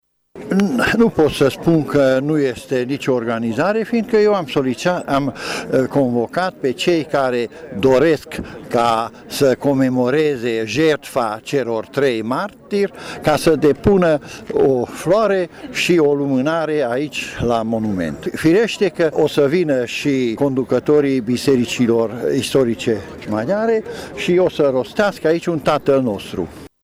Circa 450 de persoane s-au strâns, de la ora 16,00, la Monumentul Secuilor martiri din Tîrgu-Mureș, pentru a comemora Ziua Libertății Secuiești.